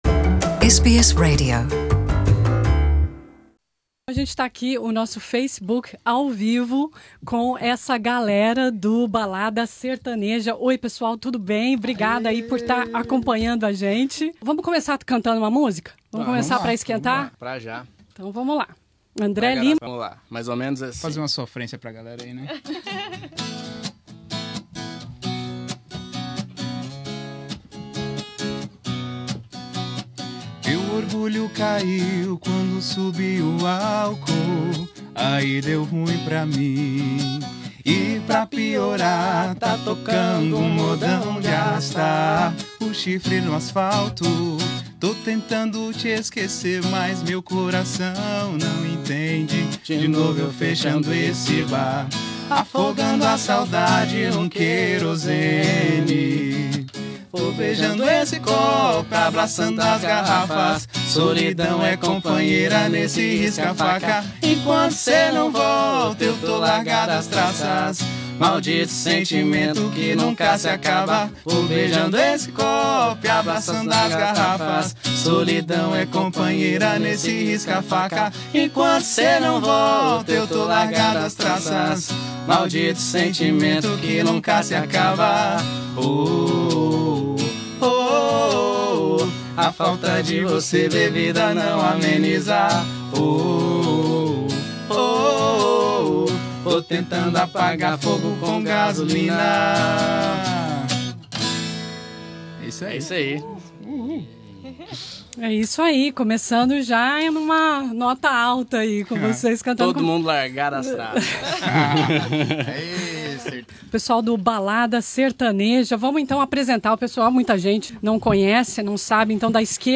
Nesssa entrevista